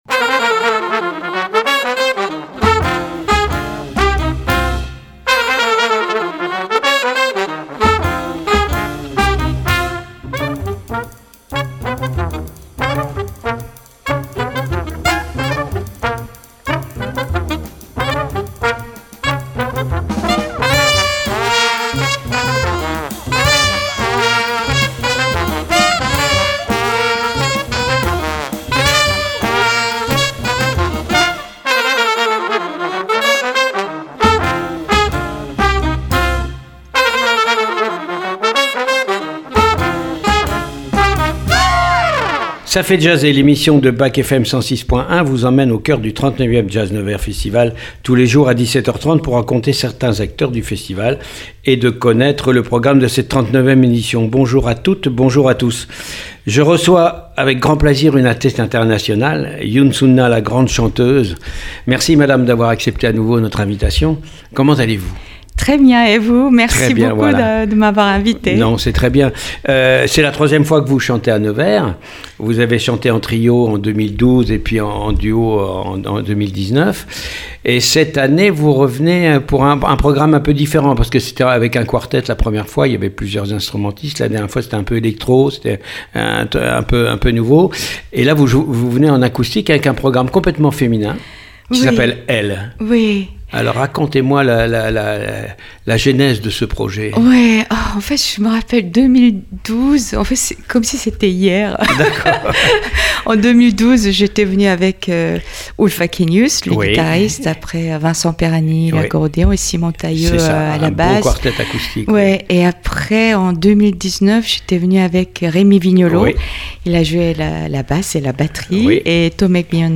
Pour cette 1ère émission en direct du Théâtre Municipal de Nevers